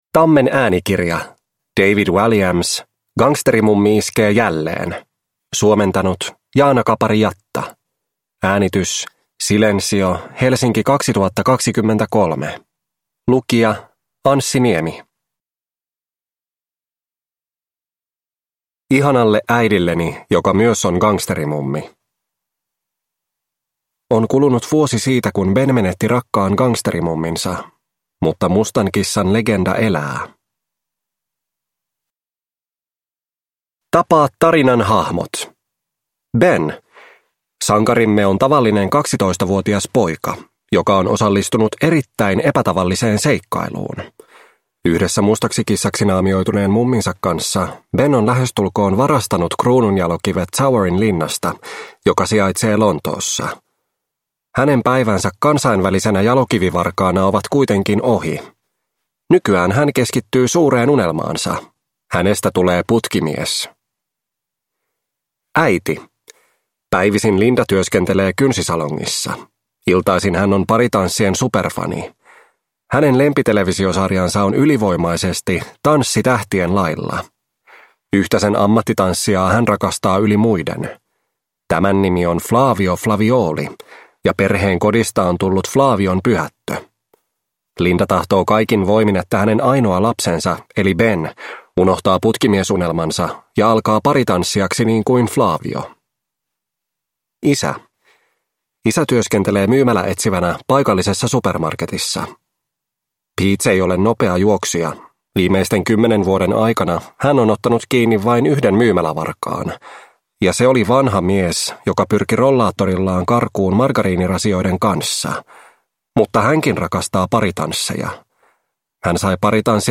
Gangsterimummi iskee jälleen – Ljudbok – Laddas ner